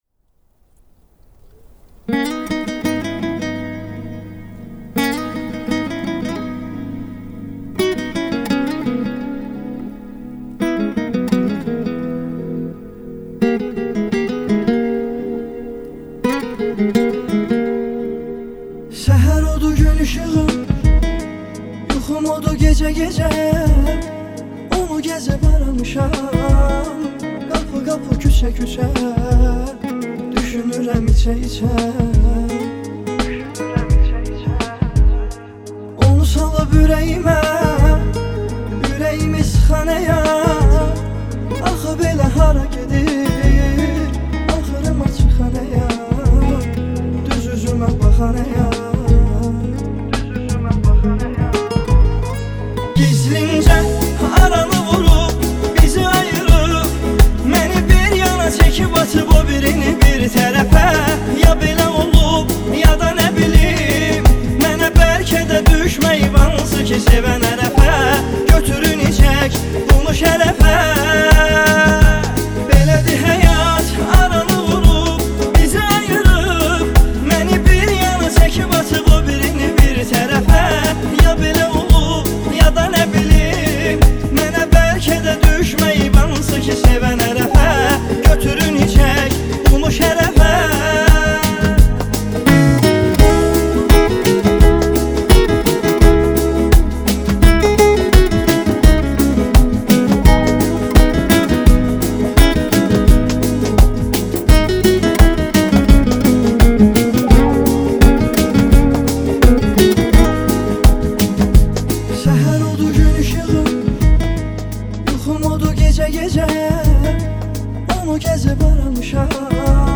آهنگ ترکی غمگین